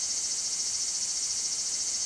cicadas_day_loop_02.wav